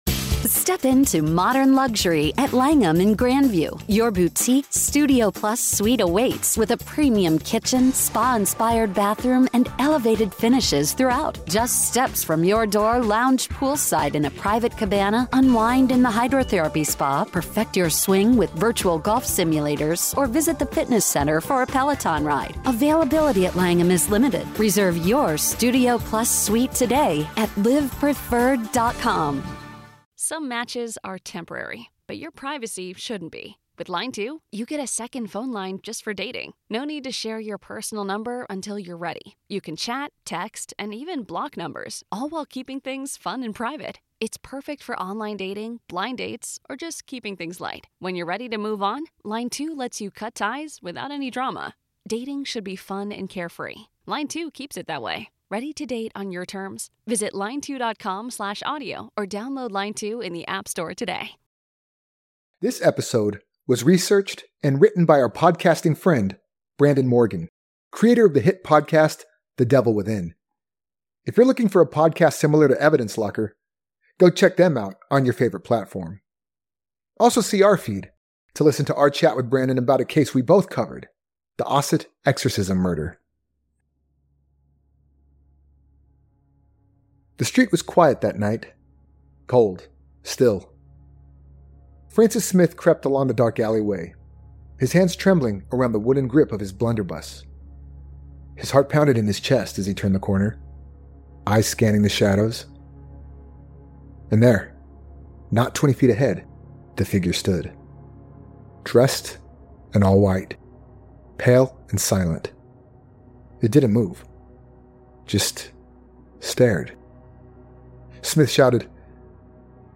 Narrated
Background track